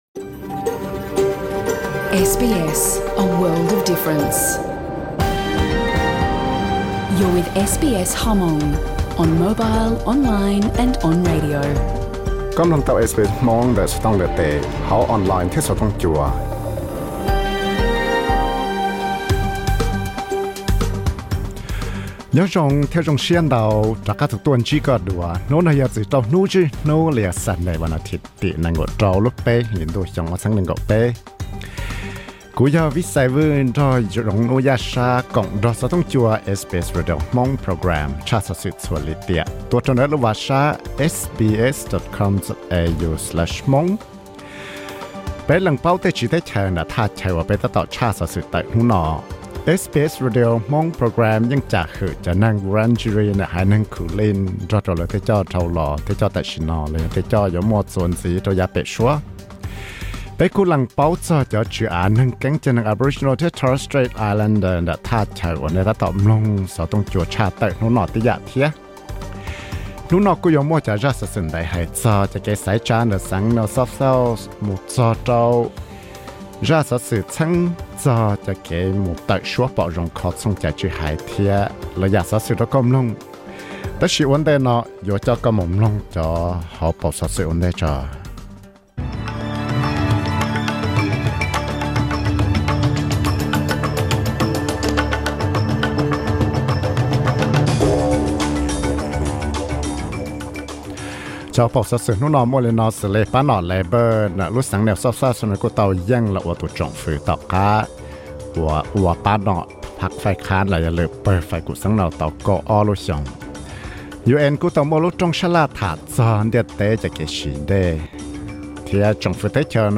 Xov xwm hnub zwj Hnub (Sunday news 26.03.2023), referendum yog dab tsi thiab muaj tej txheej txheem zoo li cas, vim li cas thiaj rov qab muaj neeg mob ntsws ntau tuaj ntxiv hauv ntiaj teb no lawm?